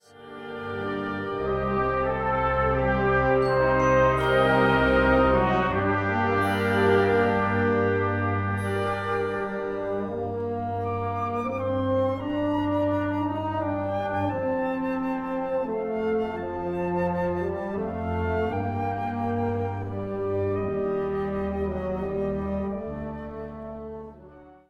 Komponist: Volksweise
Besetzung: Blasorchester
A unique and vibrant setting of the well-known melody.